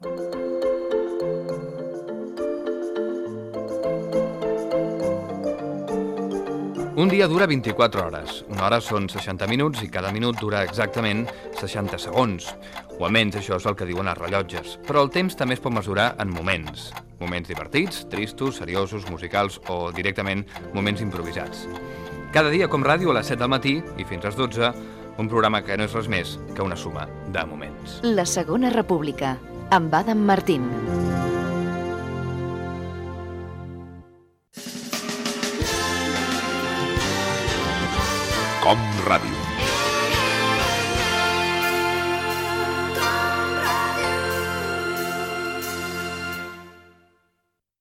Promoció del programa i indicatiu de l'emissora
FM